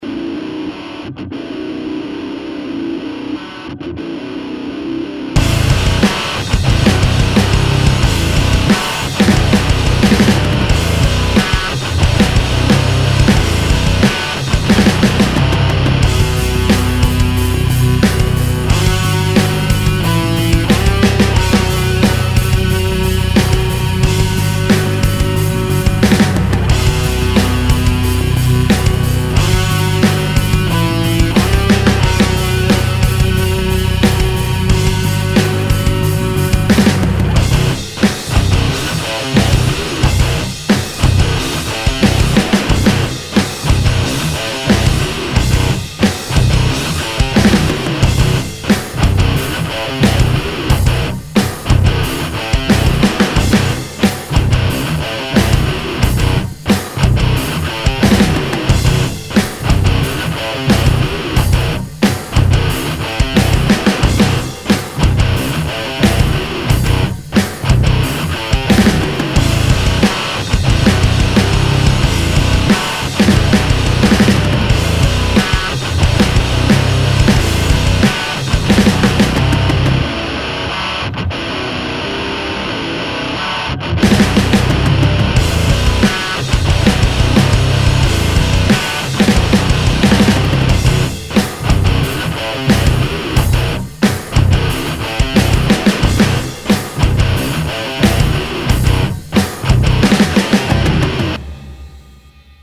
Qq riffs rock avec la AMT R1 (mais je ne me rappelle plus si j'ai utilisé le torpedo live ou le PI-101 sur celle la).